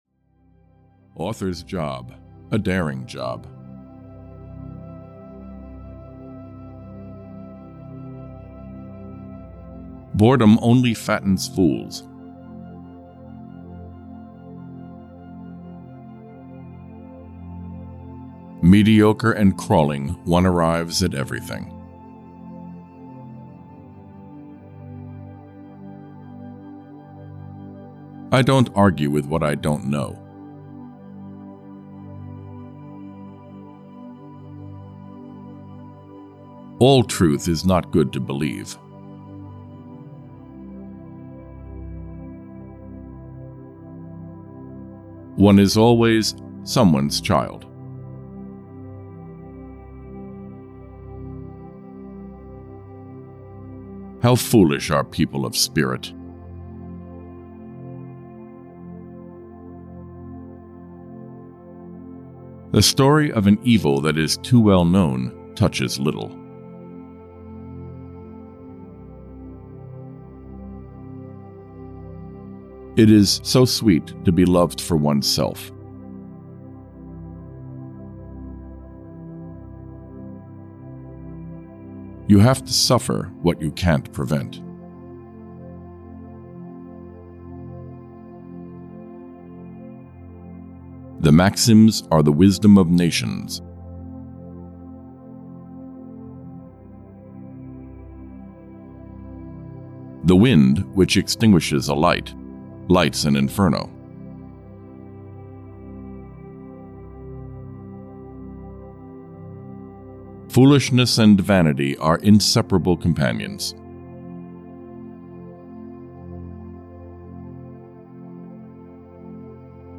Audio kniha600 Quotations from the Great Writers of the 17th Century (EN)
Ukázka z knihy